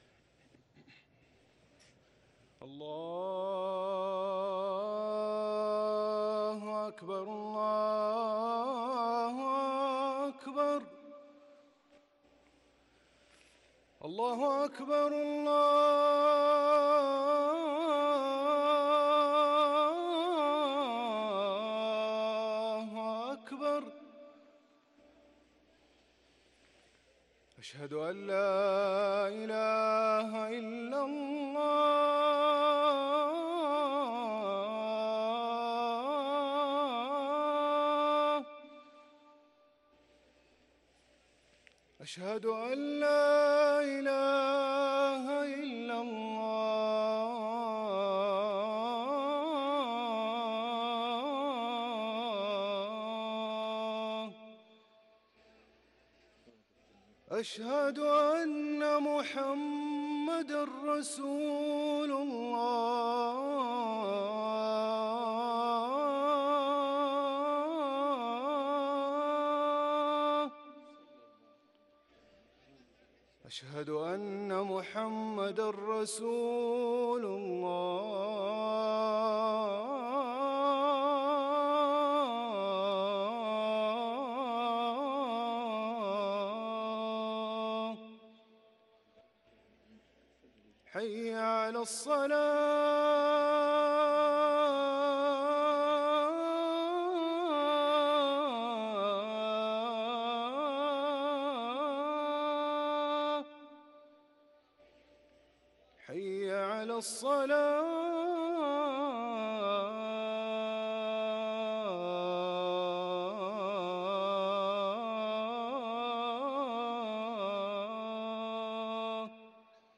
أذان المغرب
ركن الأذان